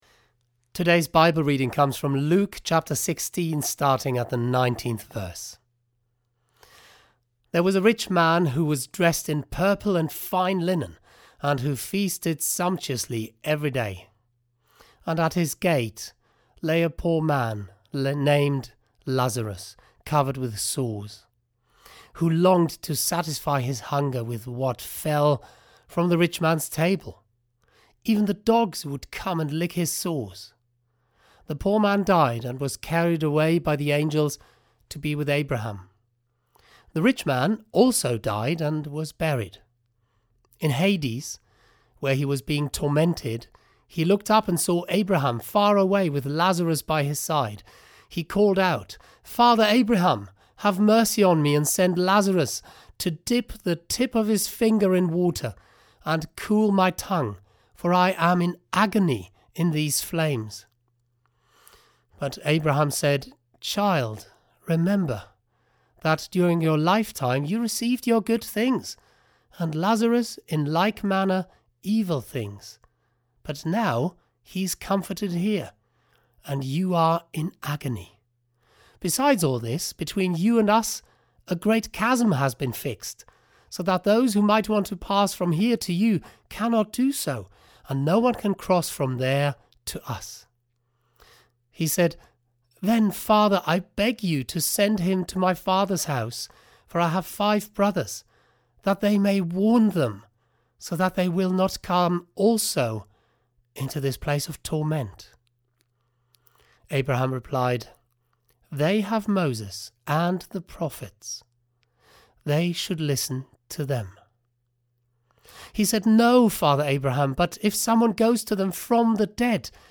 Sermon: Where is God